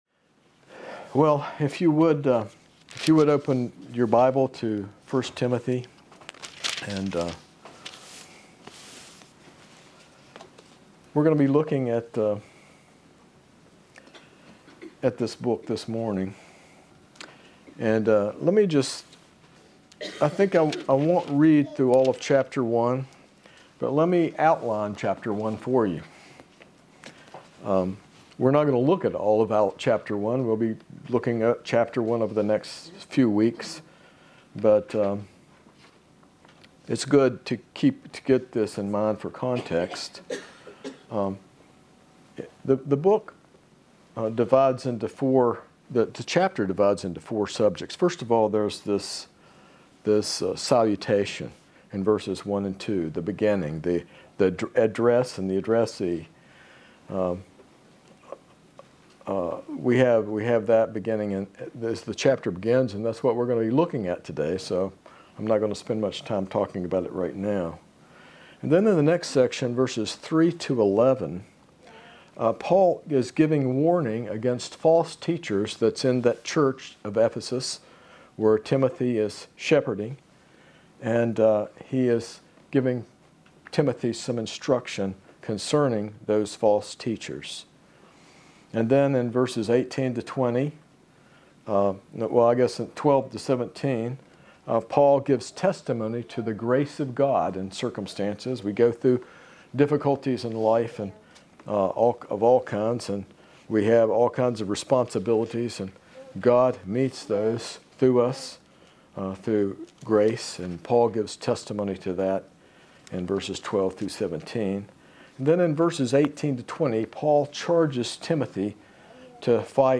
This is the first message in a new sermon series at Mayflower Hills Baptist Church on the book of 1 Timothy.